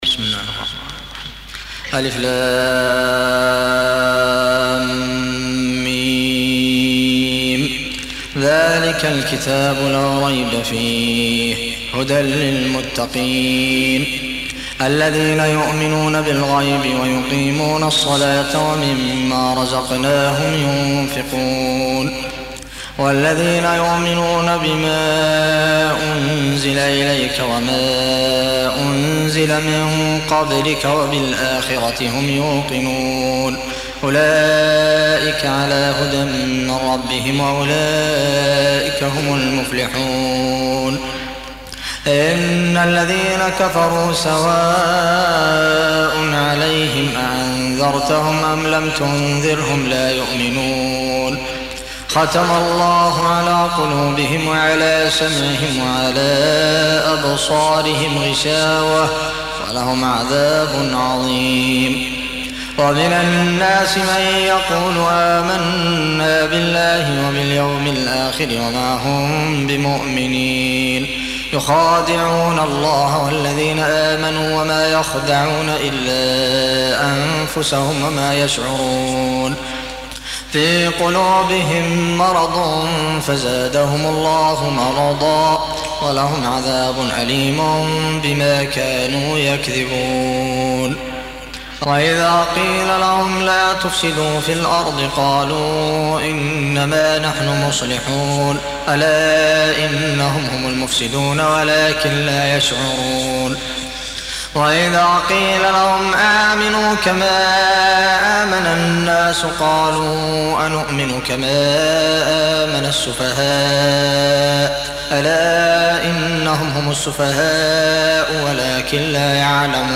Surah Repeating تكرار السورة Download Surah حمّل السورة Reciting Murattalah Audio for 2. Surah Al-Baqarah سورة البقرة N.B *Surah Includes Al-Basmalah Reciters Sequents تتابع التلاوات Reciters Repeats تكرار التلاوات